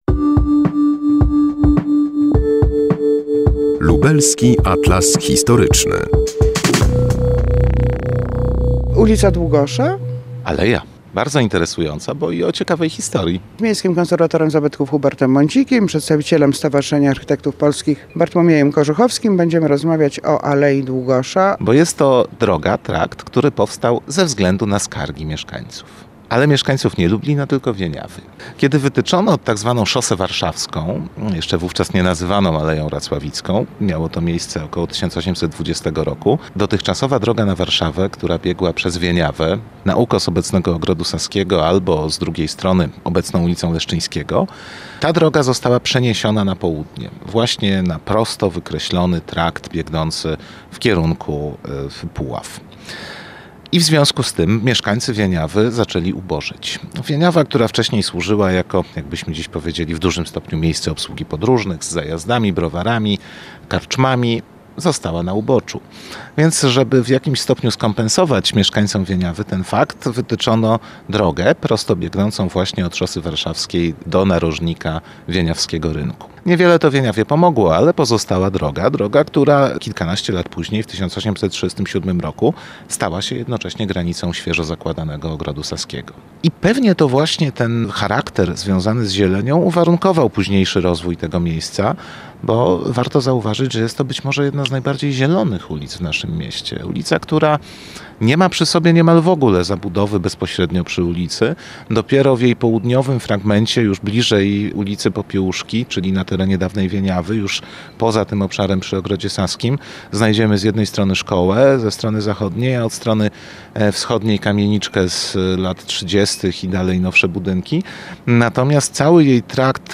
Al. Jana Długosza w Lublinie – bardzo interesująca i o ciekawej historii. O tym rozmawiamy